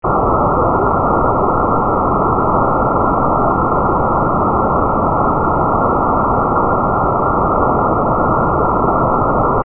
Demo 2: Pitch Identification
The signal ITD is fixed to lead one ear by 0.6 ms, so it will be perceived to be to one side of the central background noise. However, each 3-note sequence is either rising (400 Hz, 500 Hz, 600 Hz) or falling (600 Hz, 500 Hz, 400 Hz).